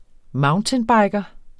Udtale [ ˈmɑwntənˌbɑjgʌ ]